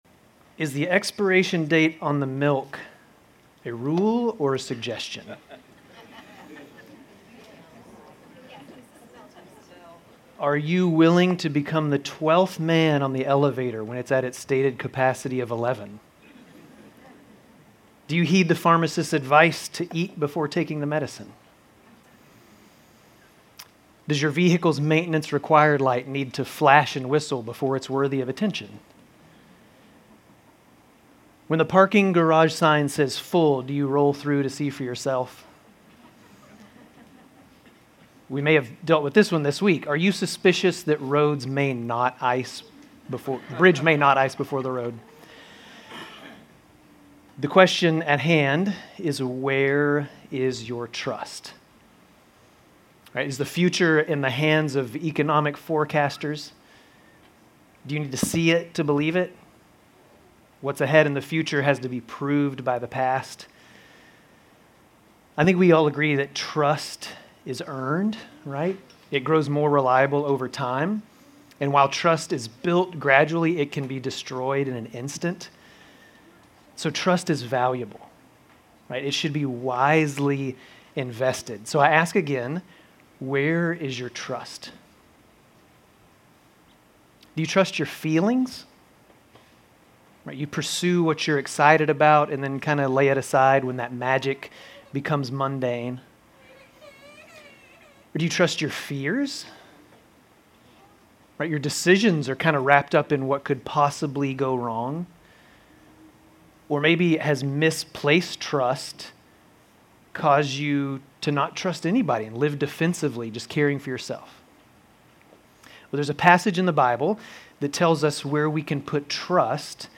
Grace Community Church Dover Campus Sermons Jan 12 - Prayer Jan 13 2025 | 00:20:43 1x 00:00 / 00:20:43 Subscribe Share RSS Feed Share Link Embed